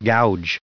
added pronounciation and merriam webster audio
876_gouge.ogg